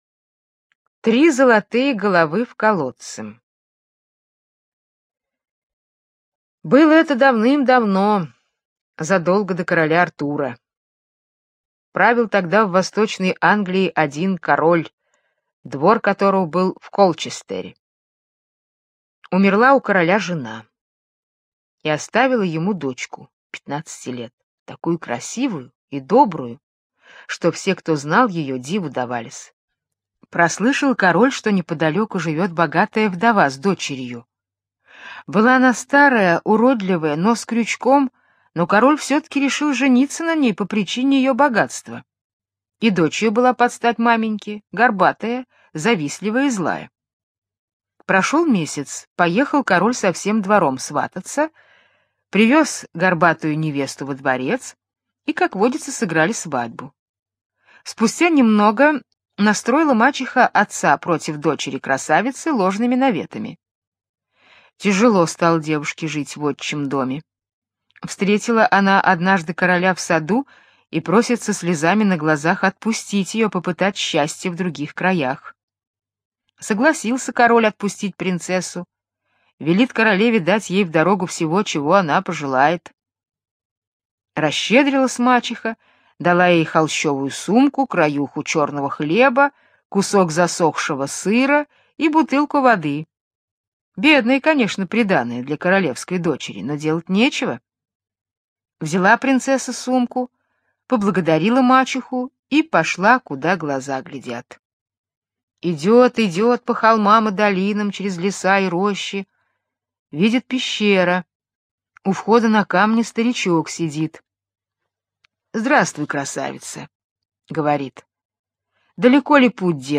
Три золотые головы в колодце – британская народная аудиосказка